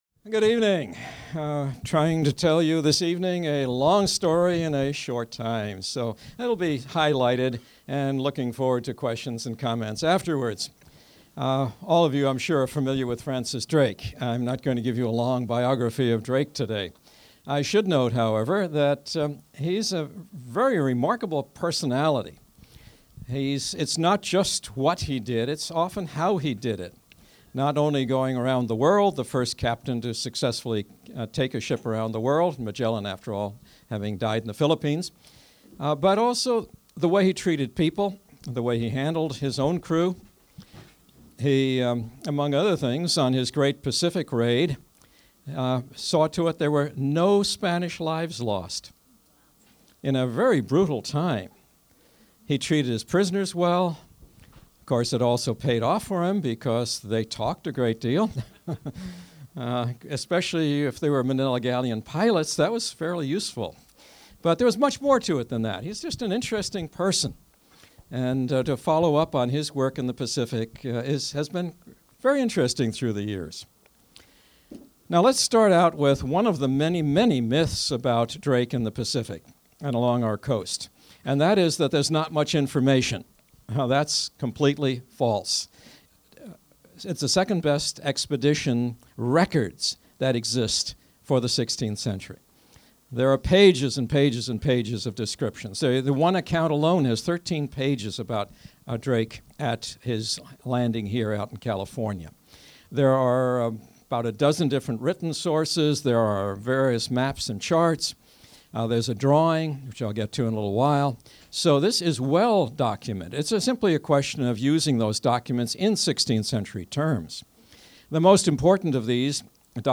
The idea that Drake was a decent man in a brutal age is not a new one. Listen to a 2012 presentation at the Inverness Yacht Club Lecture Part 1